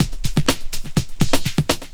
21 LOOP06 -L.wav